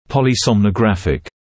[ˌpɔlɪˌsɔmnə’græfɪk][ˌполиˌсомнэ’грэфик]полисомнографический